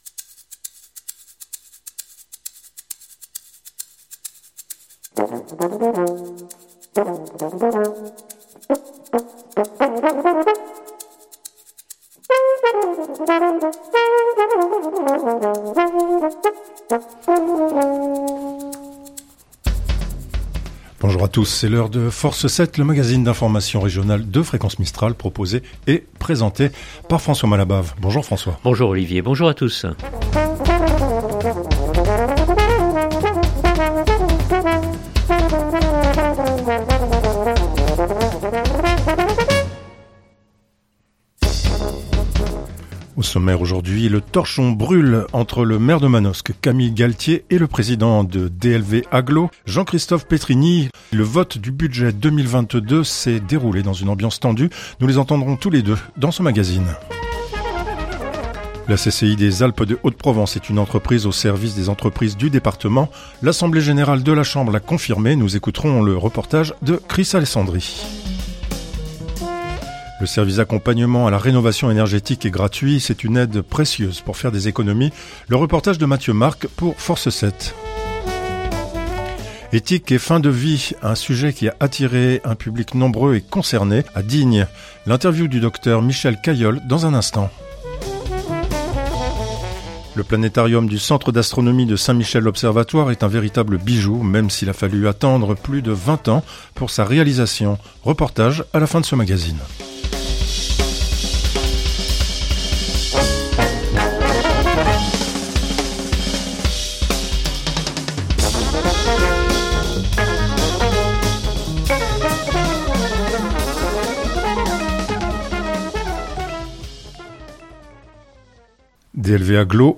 Reportage à la fin de ce magazine.